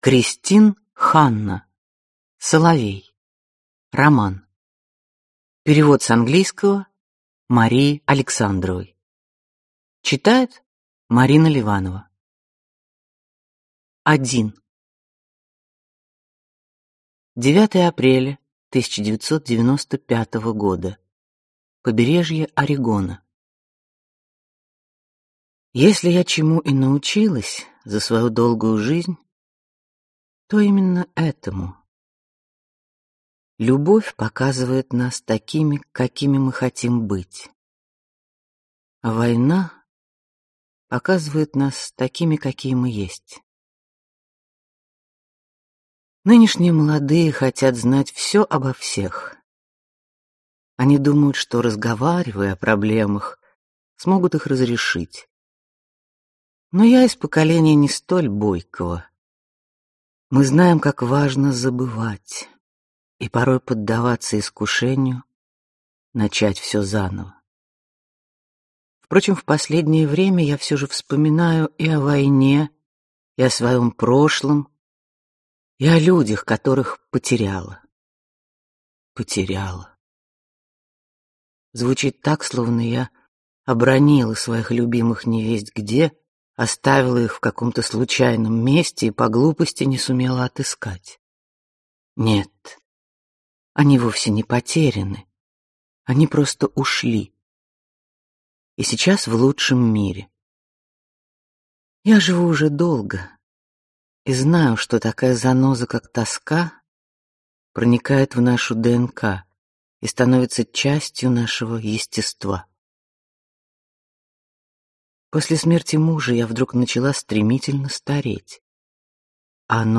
Прослушать фрагмент аудиокниги Соловей Кристин Ханна Произведений: 3 Скачать бесплатно книгу Скачать в MP3 Вы скачиваете фрагмент книги, предоставленный издательством